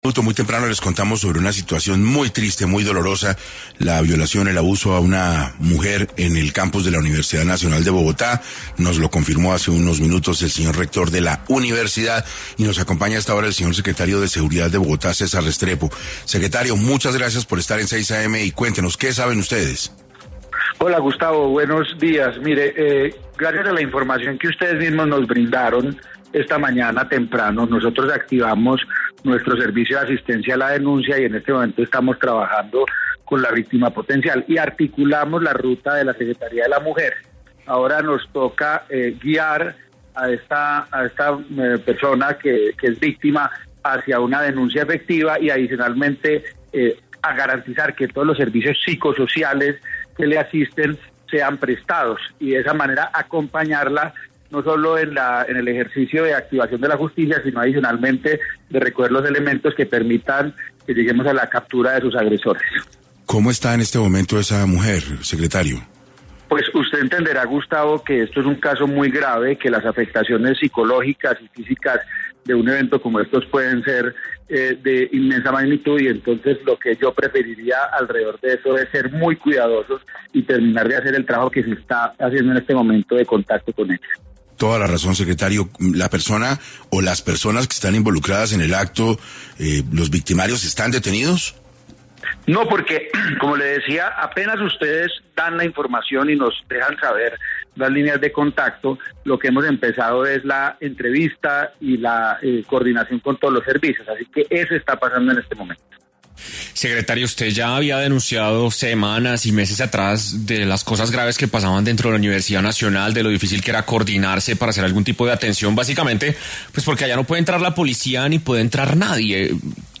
César Restrepo, secretario de seguridad, habló en ‘6AM’ de Caracol Radio y expresó su preocupación ante los hechos sucedidos en la Universidad Nacional.